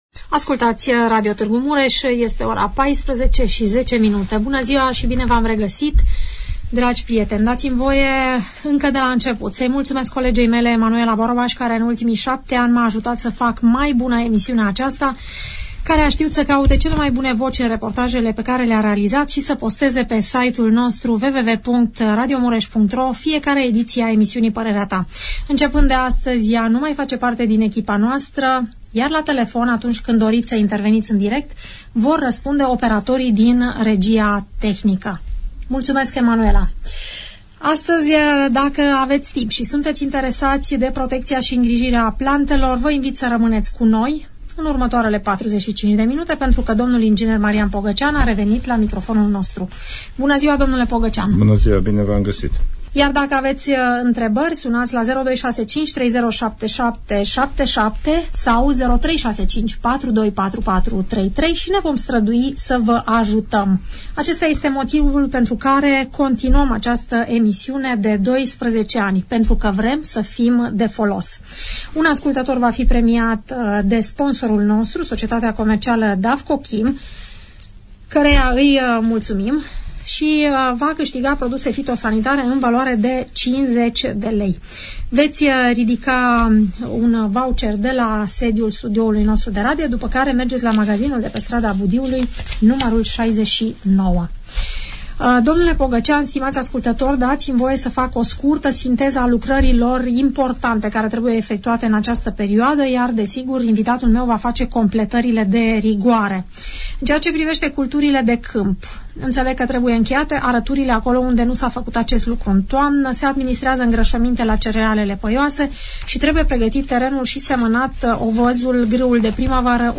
Invitat, dl dr ing.